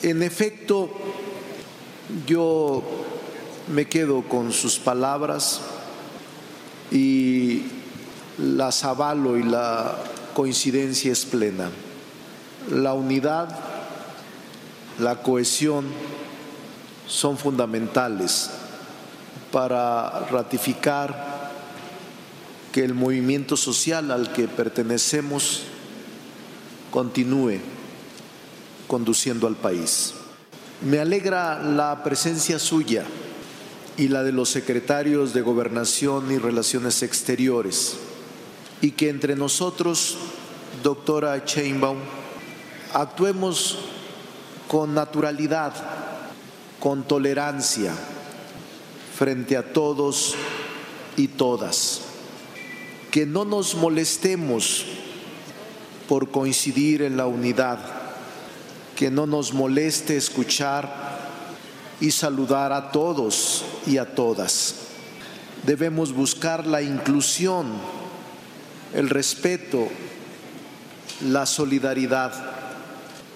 Al participar en la X Reunión Plenaria del Grupo Parlamentario de Morena en el Senado, la mandataria local reconoció “que lo único que puede afectar a nuestro movimiento es la falta de unidad”.
MONREAL-SHEINBAUM.mp3